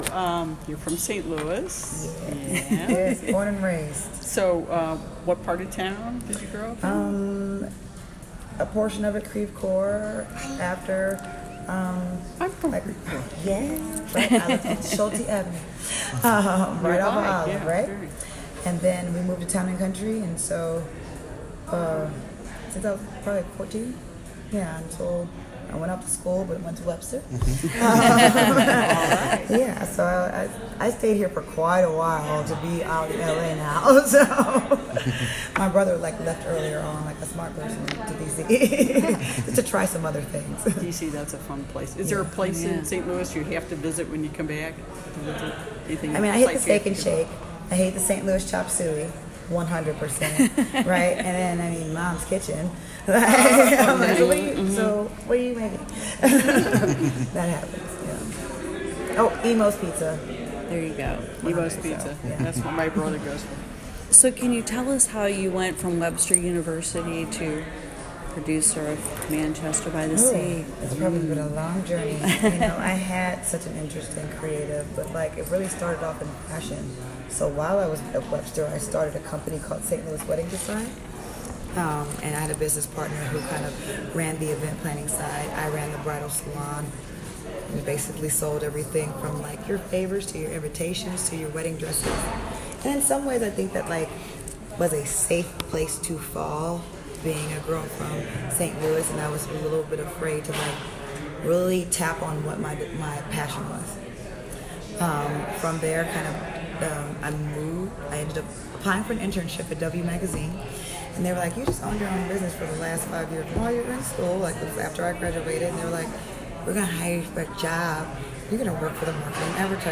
ROUND TABLE INTERVIEW